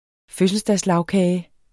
Udtale [ ˈføsəlsdas- ]